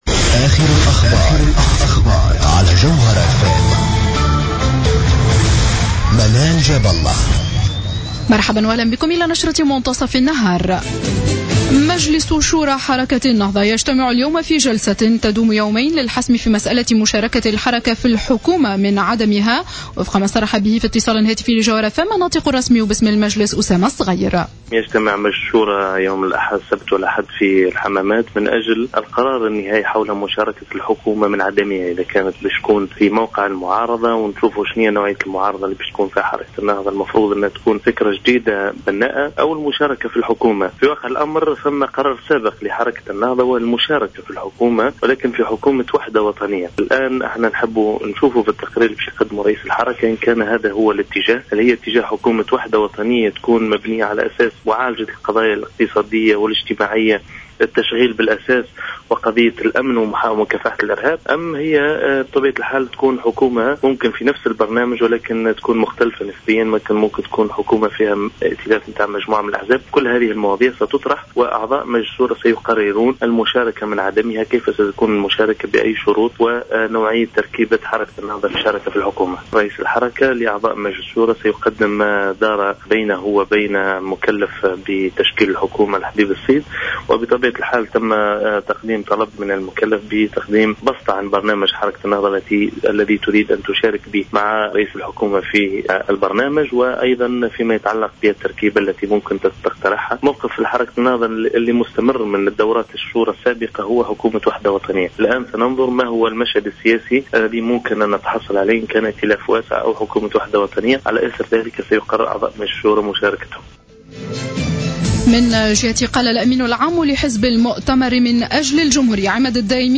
نشرة أخبار منتصف النهار ليوم السبت 10-01-15